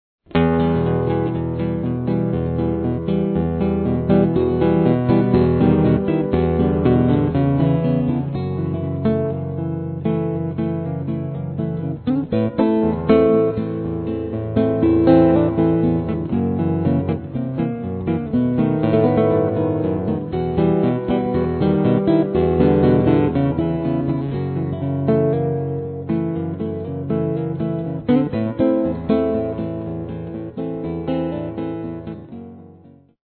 guitare cordes nylon
Percussions, basse, guitares (électrique & acoustique)